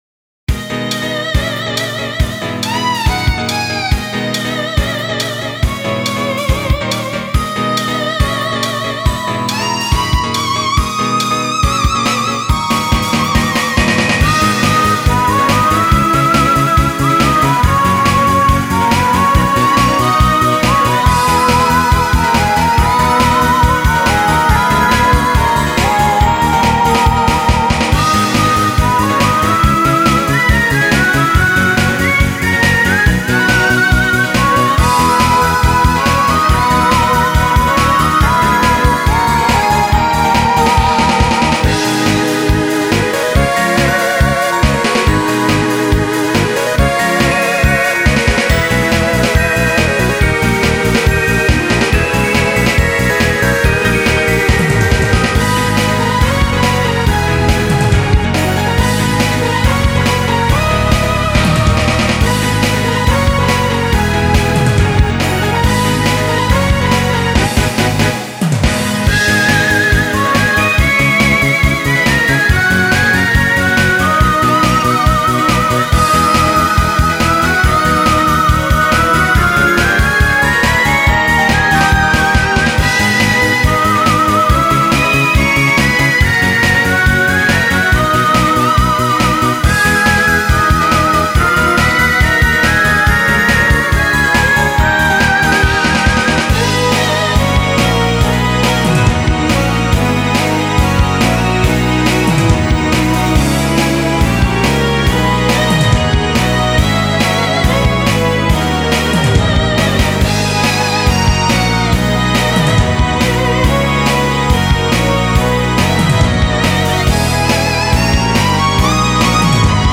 MIDIアレンジ
使用音源は、お古いDTM『SC-8850/SC-88Pro』の２台を使って作曲しています。
★フルアレンジ版 For SC-8850/SC-88Pro